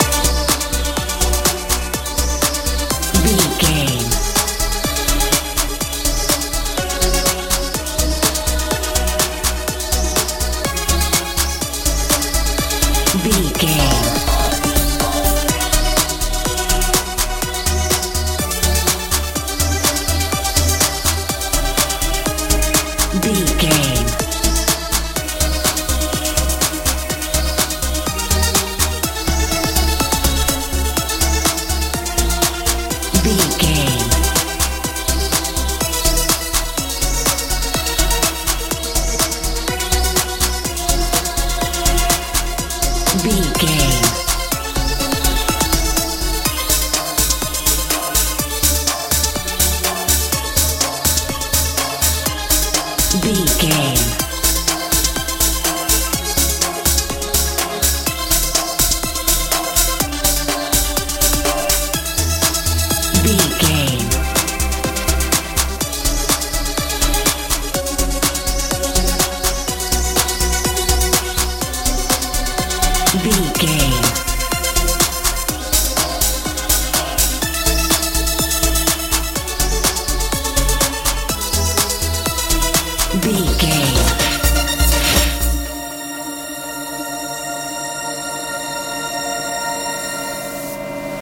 modern dance
Aeolian/Minor
magical
mystical
synthesiser
bass guitar
drums
uplifting
powerful
playful
futuristic
bouncy